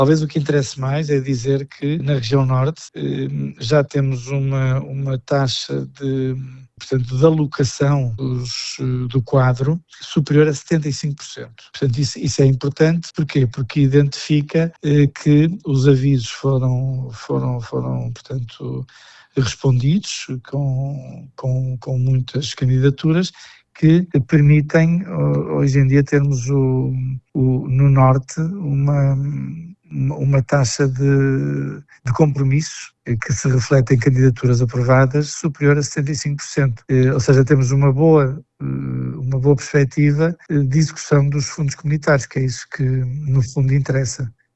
Para Pedro Lima, este nível de execução é um indicador positivo, embora destaque que a taxa de compromisso já ronda os 75%: